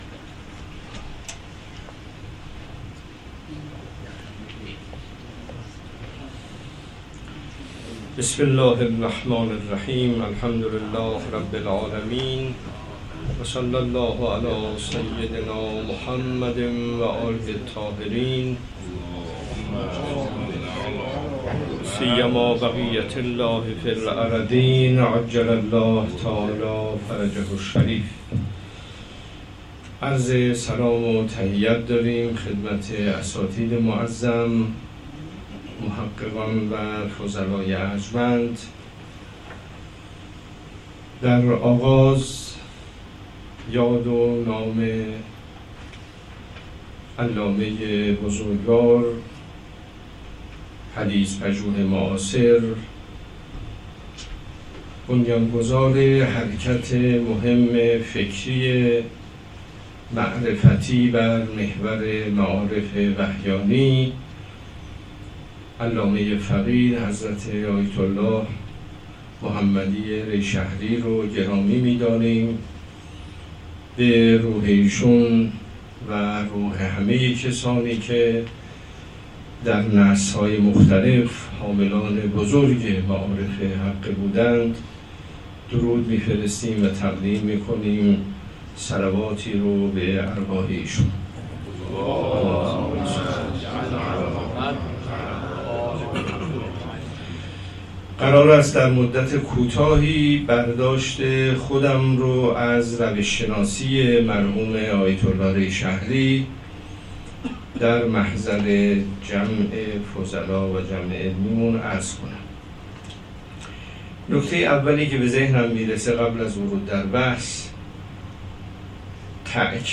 در آیین نکوداشت اندیشه های کلامی آیت الله ری شهری (ره) که در قم برگزار شد